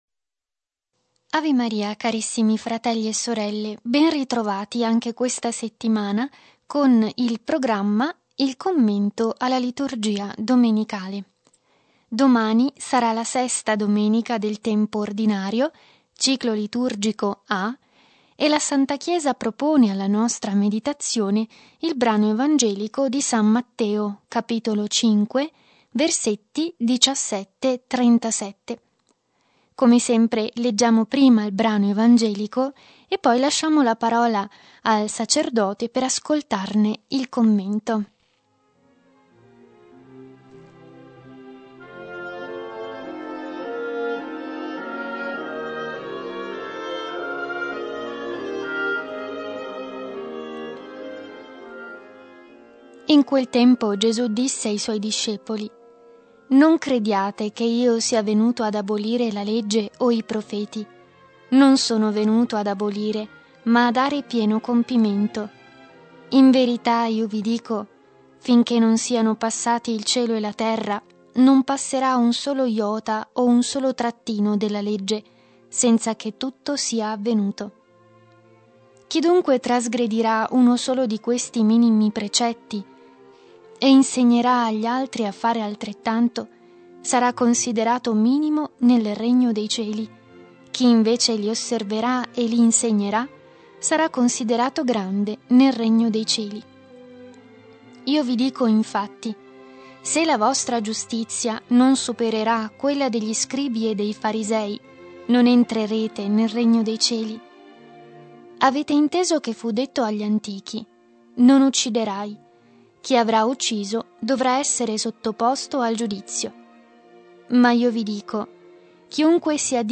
Genere: Commento al Vangelo.